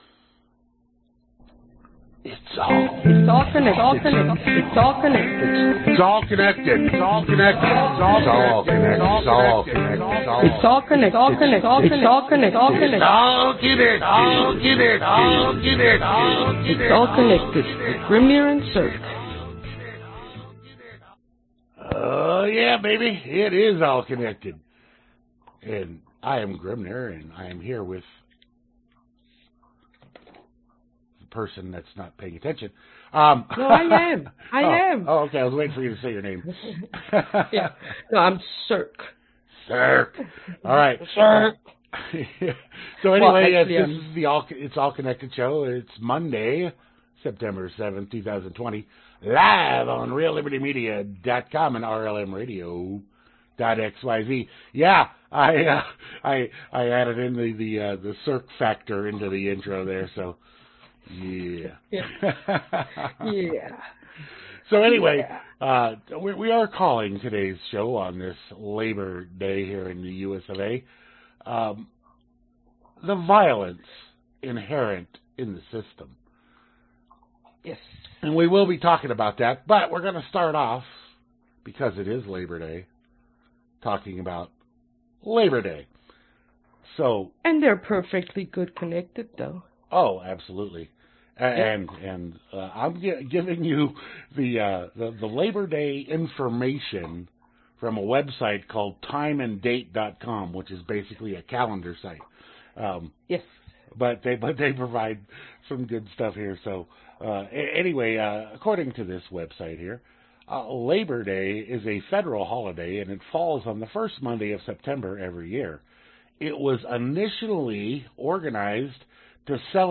Genre Talk